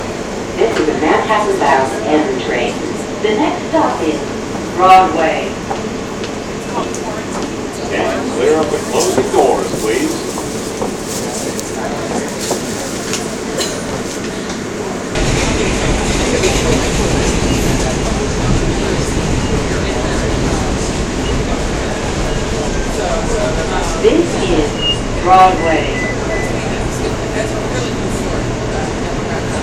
Field Rec #1
Sounds Heard: train, people, announcements
Subway, Astoria Queens
Field Recording
subway-FR.mp3